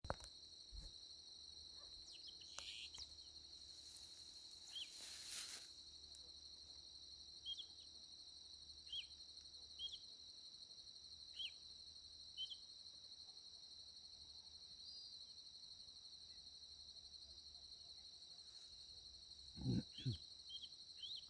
Pecho Amarillo Grande (Pseudoleistes guirahuro)
Nombre en inglés: Yellow-rumped Marshbird
Localización detallada: Ruta 13 entre Saladas y Mburucuyá
Condición: Silvestre
Certeza: Fotografiada, Vocalización Grabada
Pecho-amarillo-grande_1_1_1.mp3